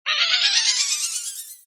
Cynical Orshroomb death noise]
cynical-orshroomb-die.opus